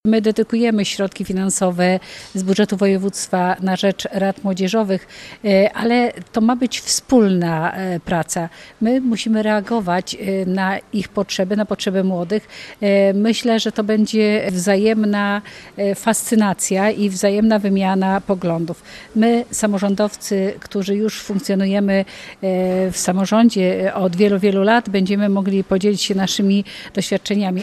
W Warszawskiej Operze Kameralnej miała miejsce pierwsza Konferencja Młodzieżowych Rad.